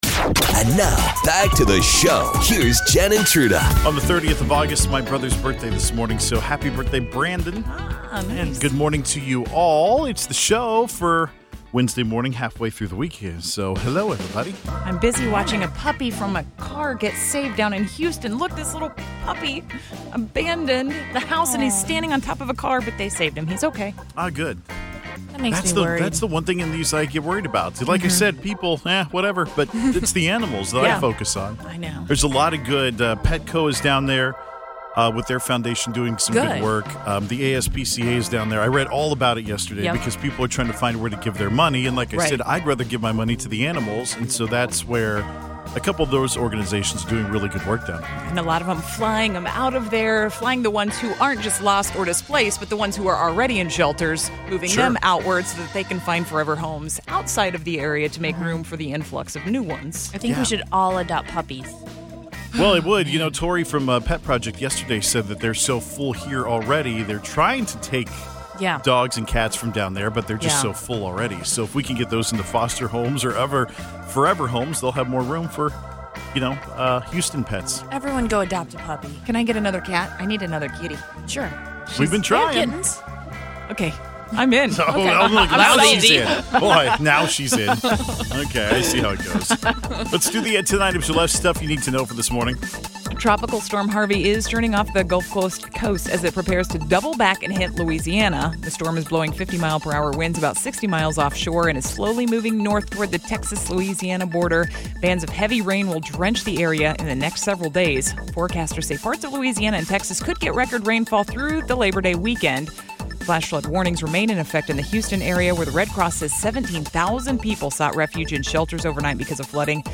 The studio was on fire.